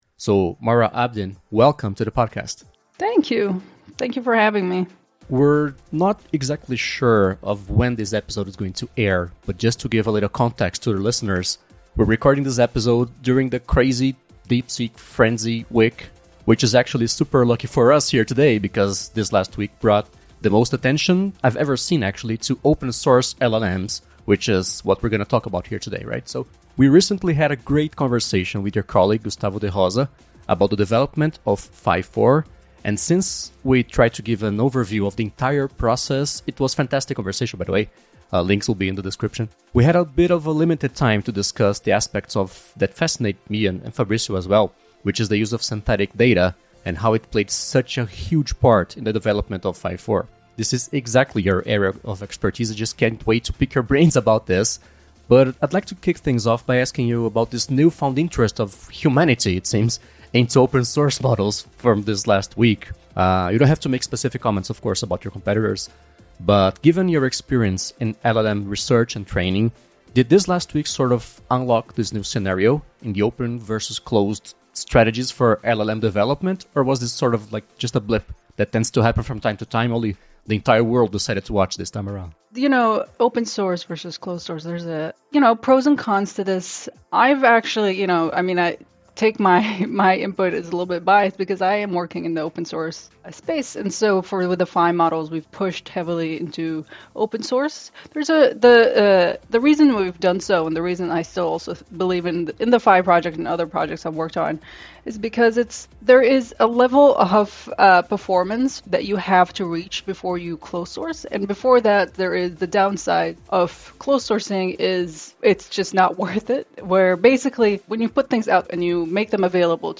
Entrevista original em inglês